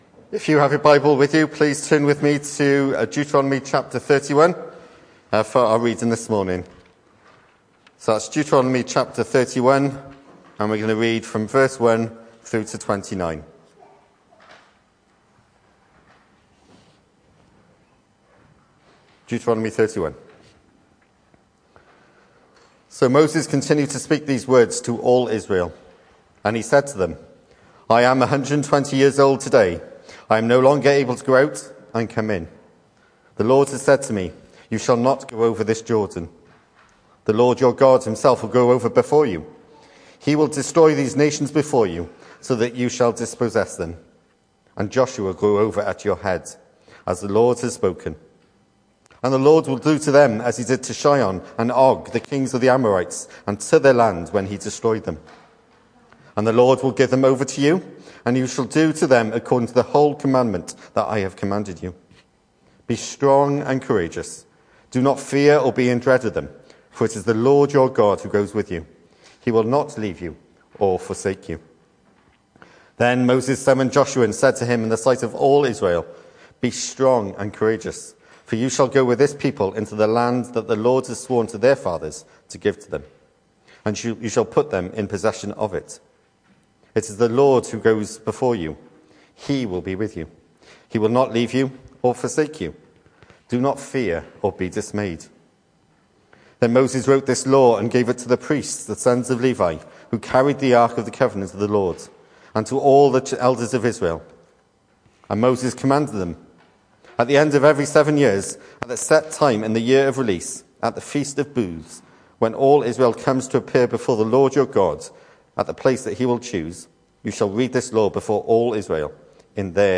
Weekly sermon recordings from an English speaking Evangelical church in West Street, Gorseinon, Swansea, SA4 4AA.